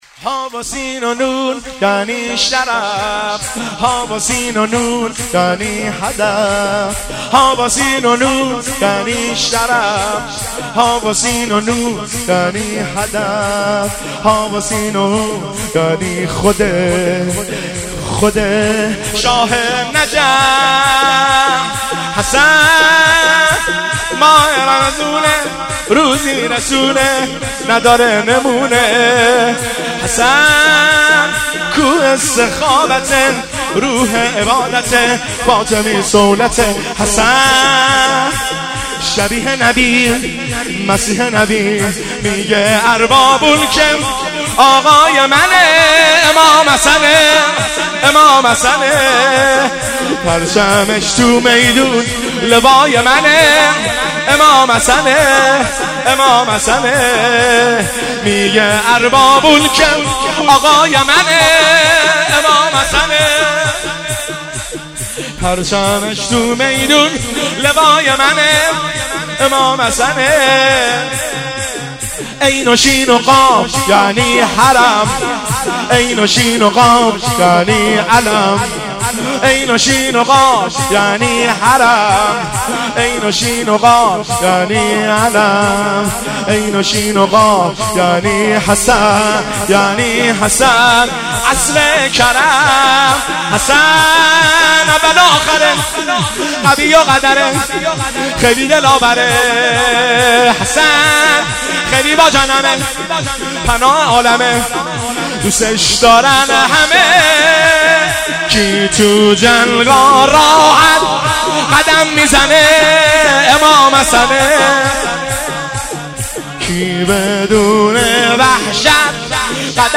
سرود میلاد امام حسن مجتبی (ع) 1402